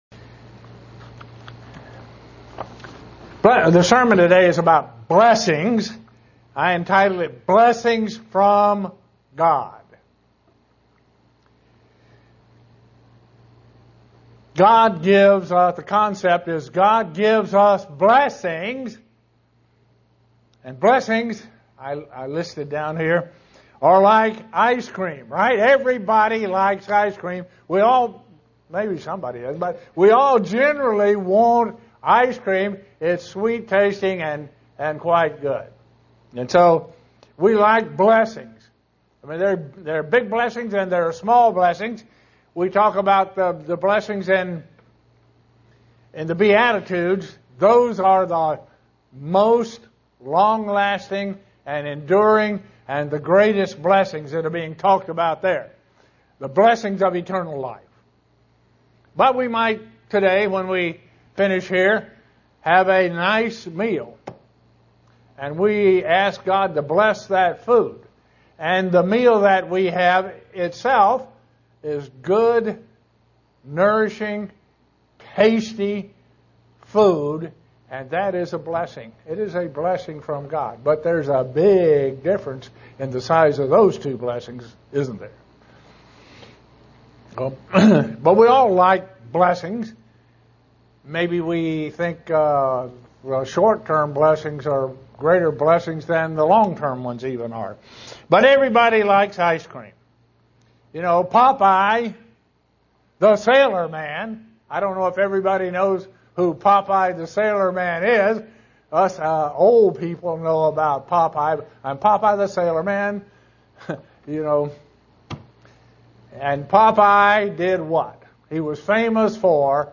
SEE VIDEO BELOW UCG Sermon Studying the bible?